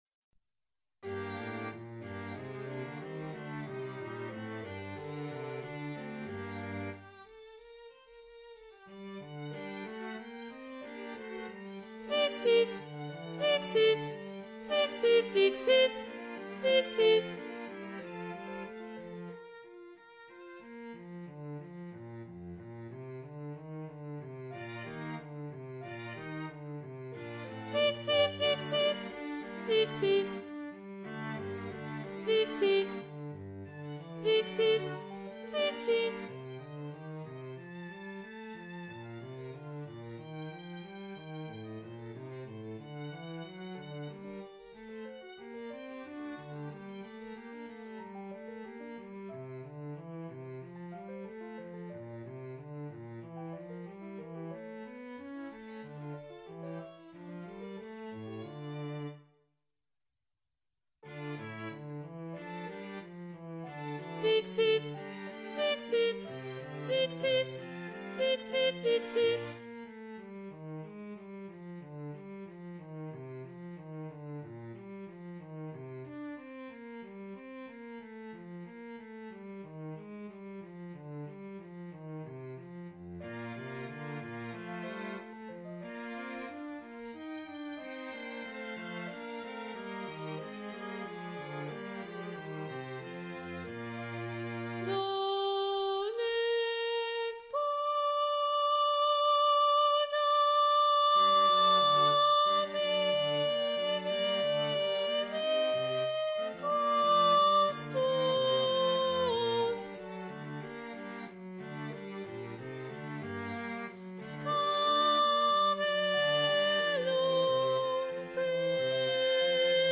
Below you will find rehearsal recordings of the movements, arranged in the order they will be sung at the concerts on December 13 and 14, 2025.
Sopranos
Emphasised voice and other voices